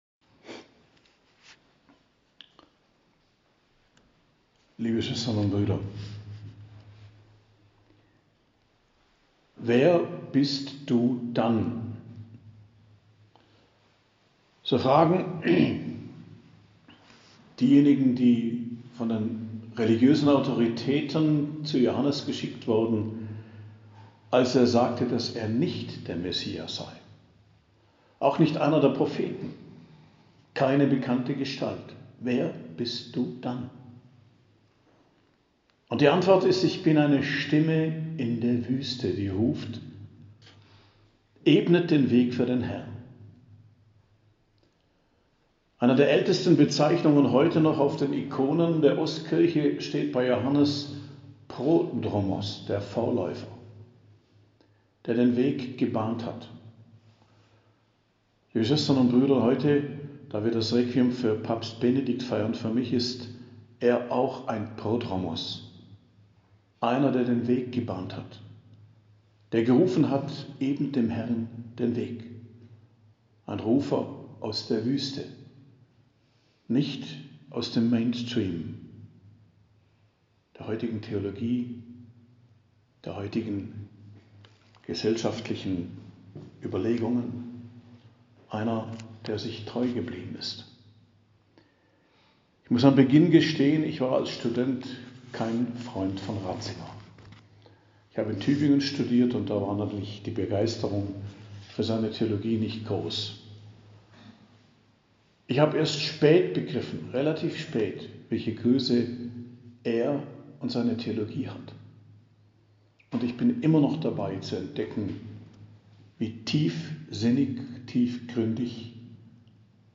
Predigt am 2.Januar in der Weihnachtszeit, 2.01.2023 ~ Geistliches Zentrum Kloster Heiligkreuztal Podcast